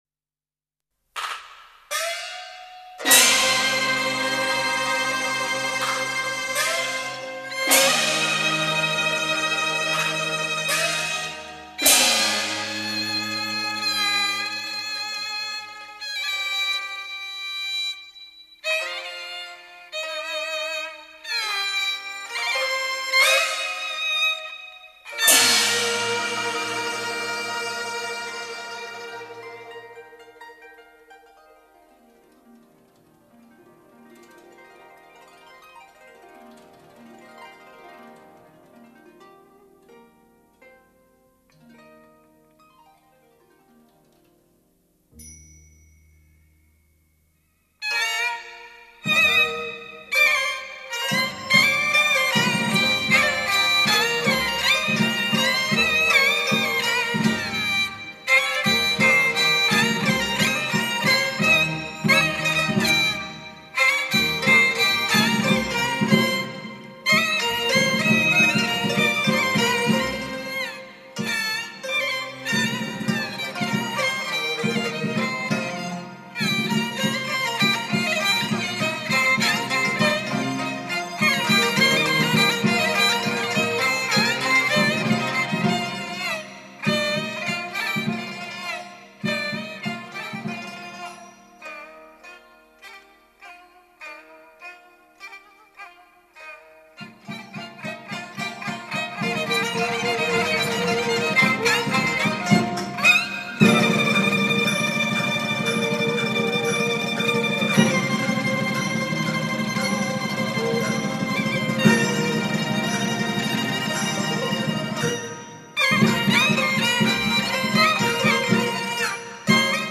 各式胡琴獨奏部份
京胡